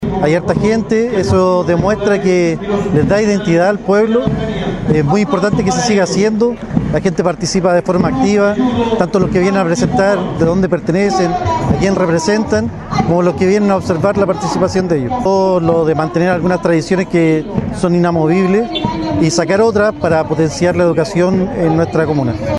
Cientos de asistentes llegaron el viernes último al frontis de la Municipalidad de Pucón, en donde prácticamente todas las fuerzas vivas de la urbe lacustre se reunieron para festejar los 143 años de existencia de esta reconocida urbe turística en un desfile cívico, del que fueron parte más de 40 organizaciones.